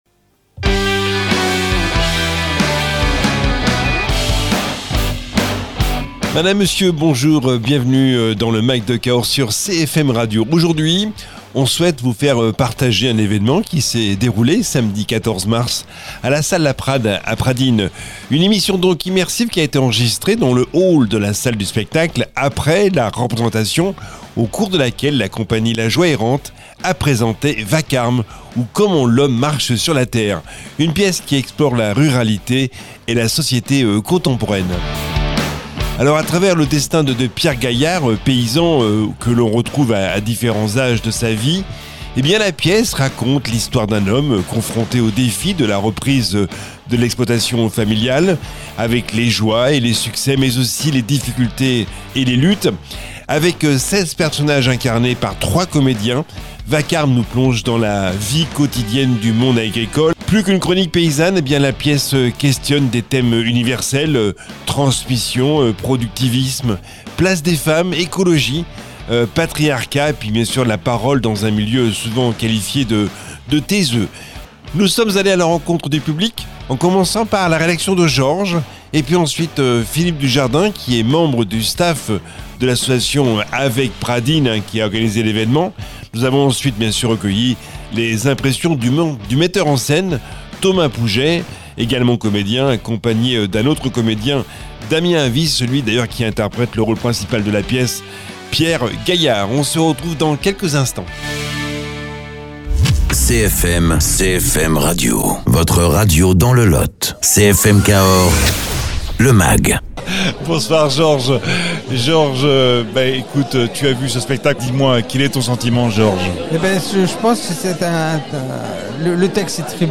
Vacarme(s) où comment l’homme marche sur la terre, une pièce dramatique a été présentée à la salle La prade à Pradines dans le Lot. Une émission immersive enregistrée dans le hall de la salle de spectacle après la représentation, au cours de laquelle la Compagnie La Joie Errante, une pièce qui explore la ruralité et la société contemporaine.